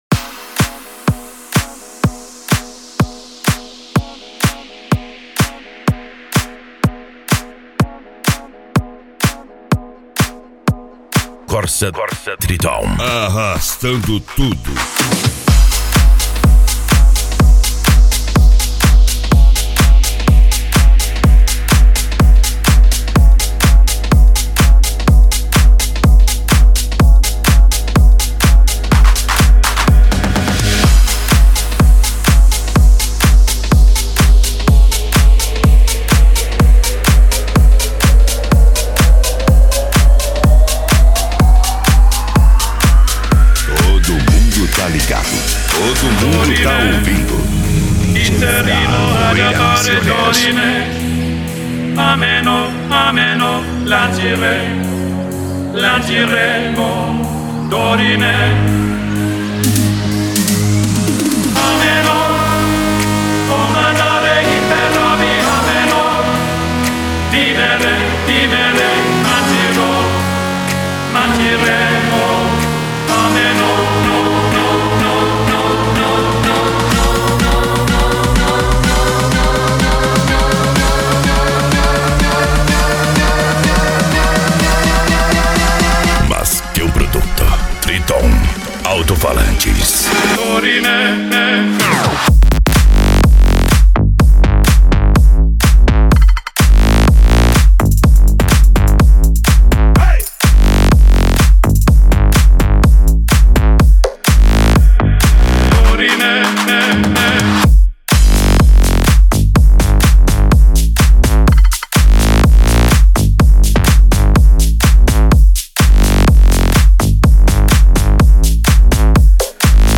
Deep House
Electro House
Psy Trance
Remix